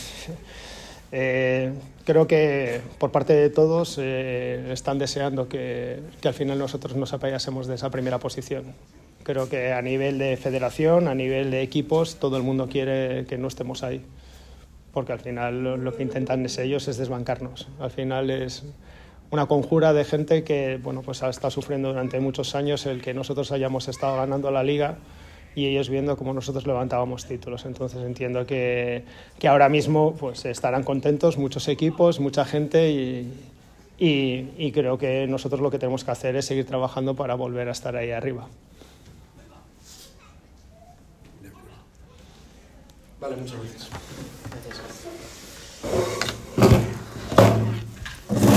Mi tono sosegado y las palabras utilizadas no muestran en ningún caso actitud defensiva, ni de crítica, como se puede escuchar en el audio adjunto, y que sí se han intentado mostrar de esa forma en los artículos publicados.